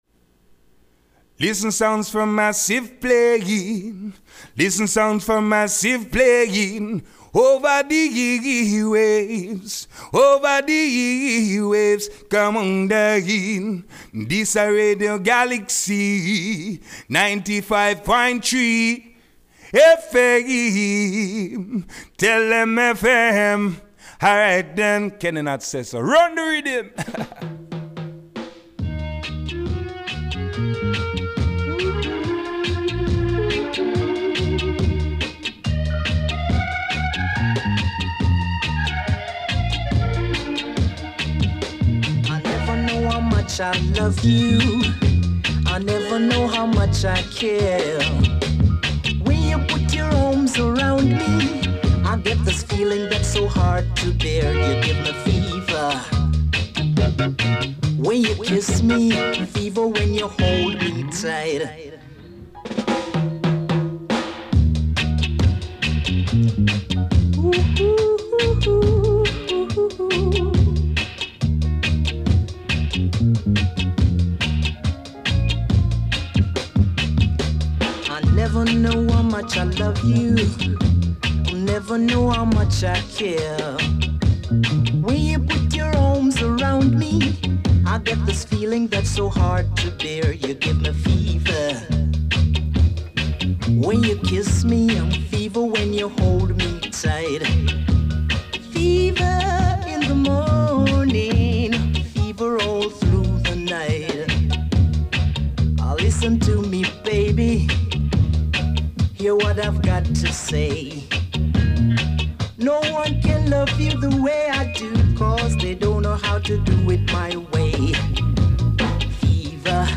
reggaephonique
enregistré hier soir dans les studios